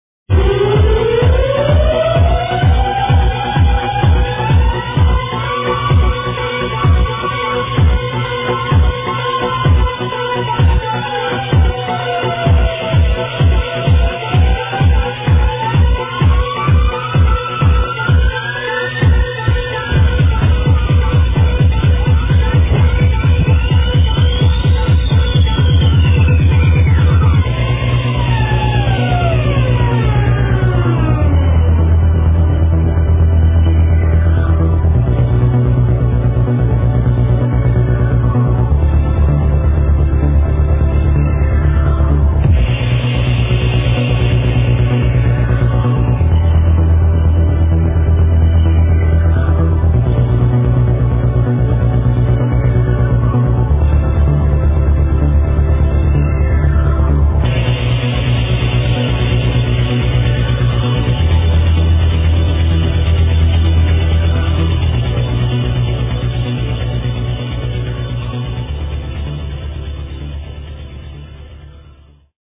Thumping huge baseline with a euphoric break !